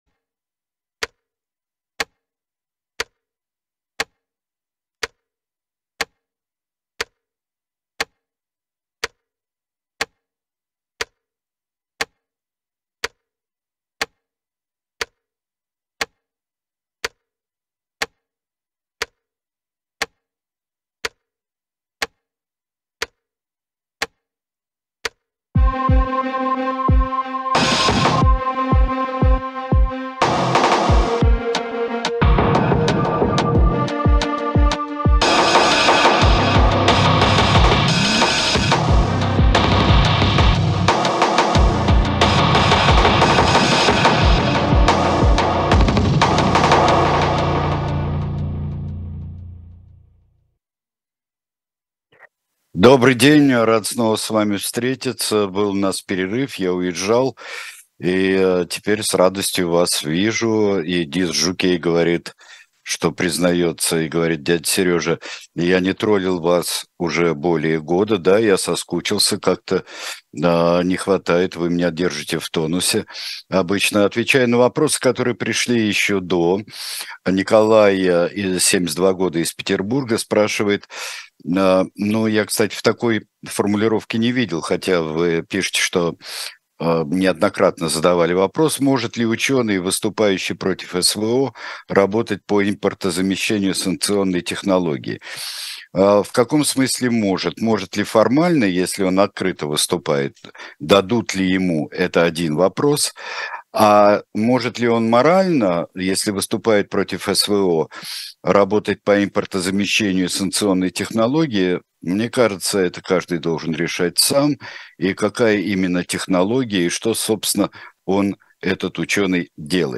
Сергей Бунтман отвечает на ваши вопросы в прямом эфире